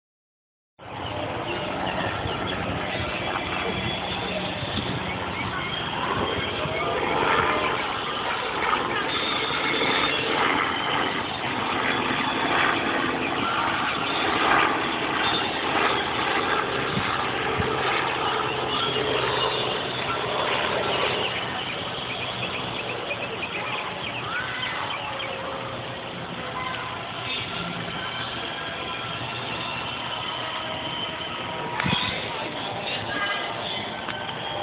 Field Recording #4
SOUNDS: Birds, water running, children laughing and splashing, plane flying overhead, music playing, wind